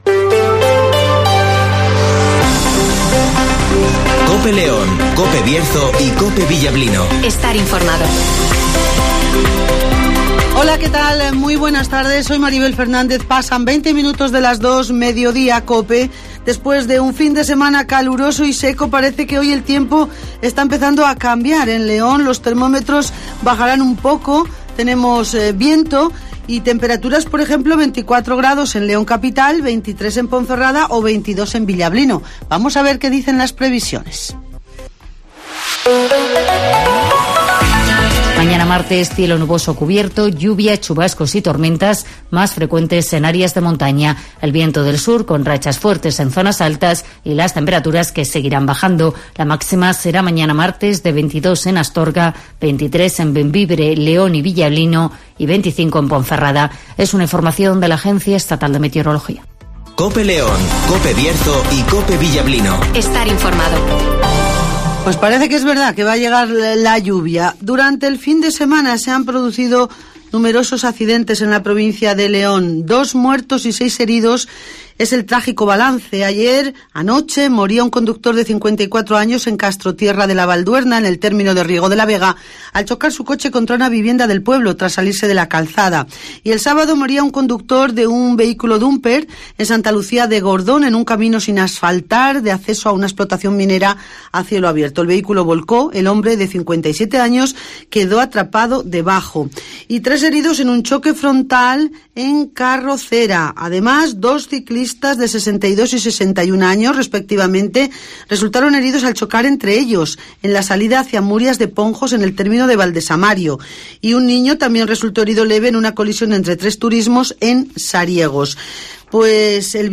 INFORMATIVOS
Repaso a la actualidad informativa de León capital, del Bierzo y del resto de la provincia. Escucha aquí las noticias con las voces de los protagonistas.